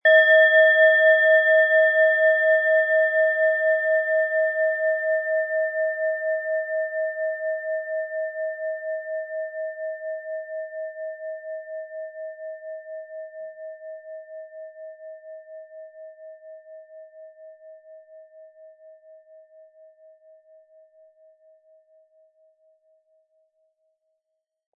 SchalenformBihar
MaterialBronze